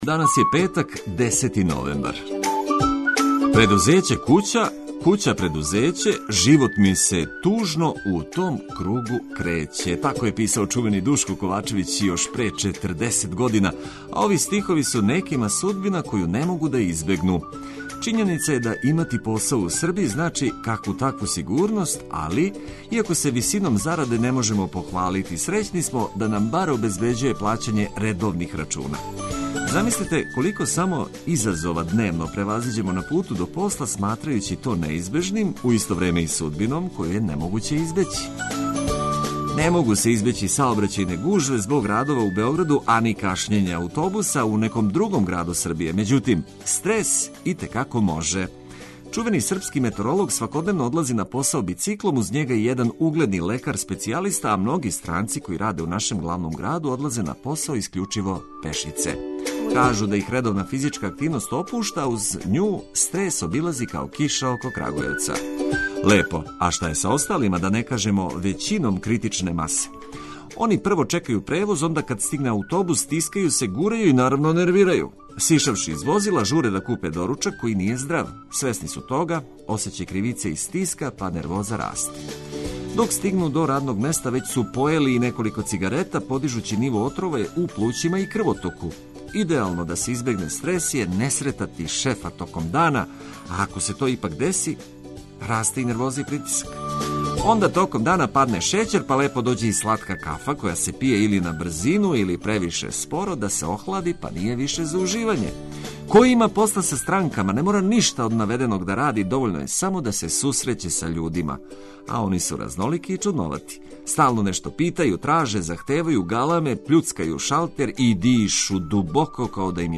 Уз хитове "за сва времена" и актуелне информације разбудићете се у пријатнијем расположењу спремни за предстојећи викенд. Како треба да изгледа исправна плинска боца, а како ће изгледати нови парк у Београду "Александров"?